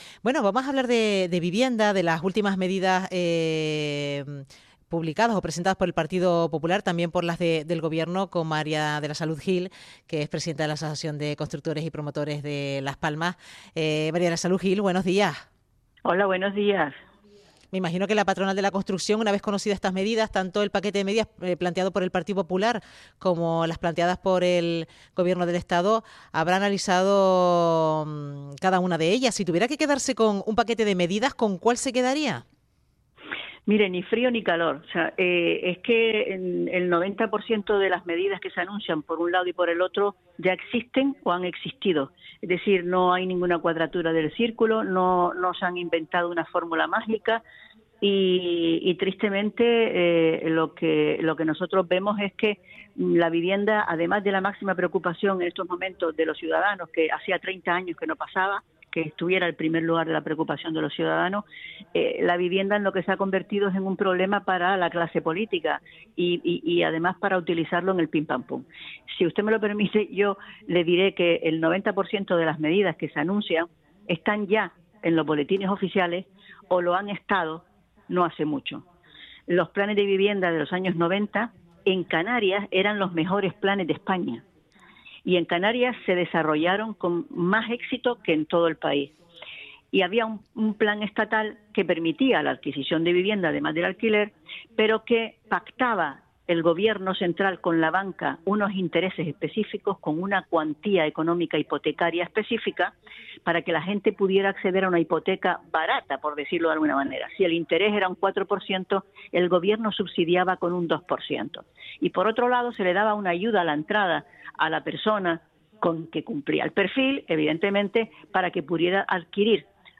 Entrevista en RTVC sobre medidas publicadas por el PP y el Gobierno del Estado.